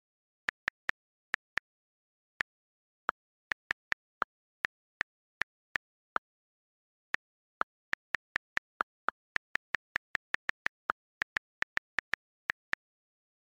В подборке — короткие и узнаваемые сигналы, которые помогут настроить мессенджер под ваш стиль.
Нестандартный звук уведомлений